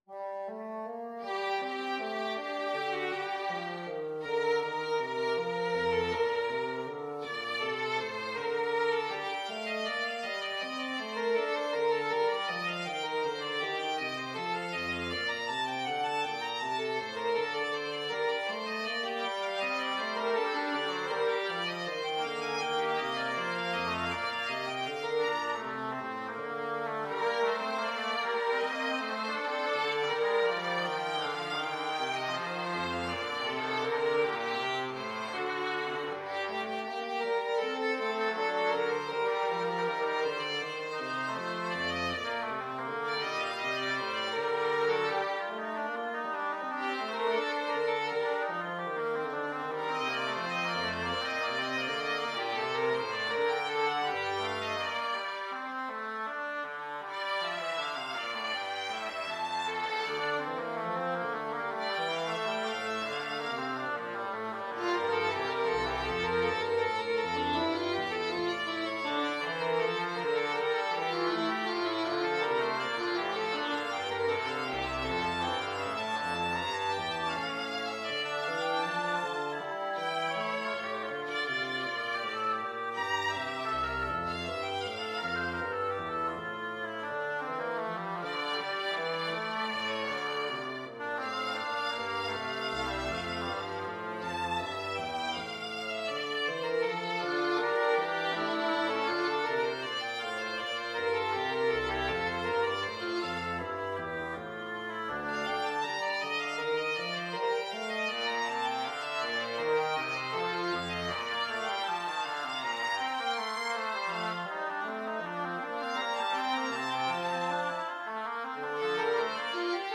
ViolinViola da GambaBass
Mixed Trio  (View more Intermediate Mixed Trio Music)
Classical (View more Classical Mixed Trio Music)